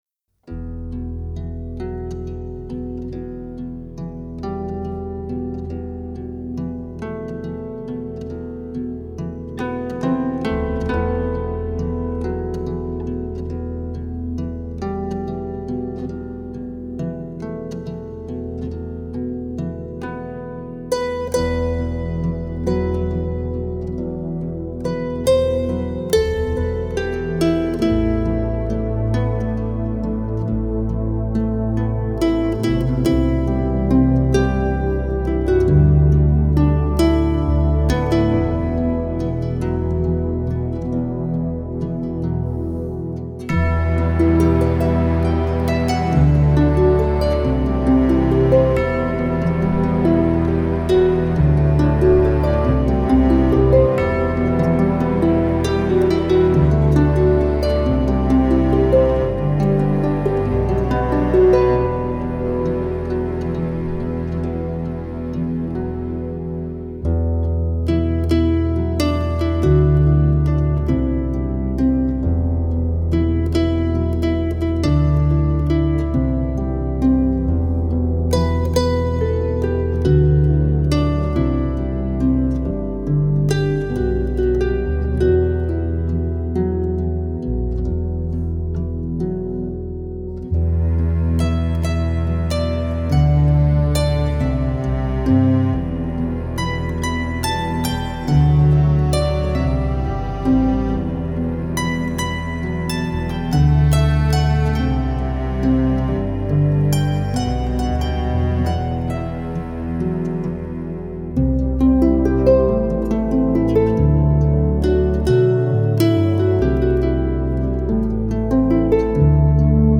0171-竖琴名曲天鹅.mp3